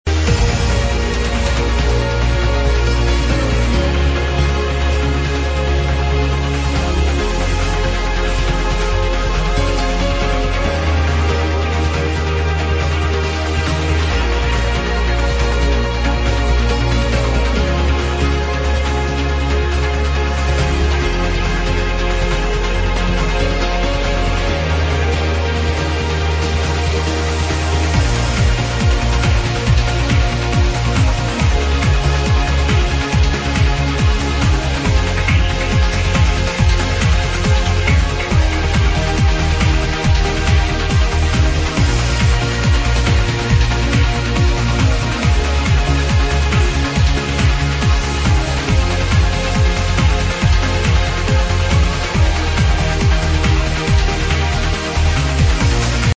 Lovely blissed chilly trance track.
NEW LINK, BETTER QUALITY, LONGER RIP